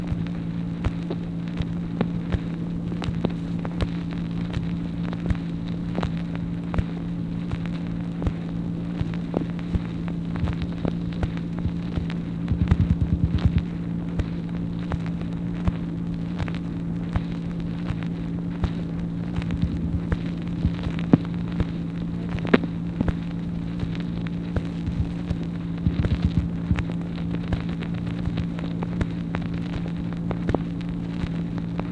MACHINE NOISE, April 10, 1964
Secret White House Tapes | Lyndon B. Johnson Presidency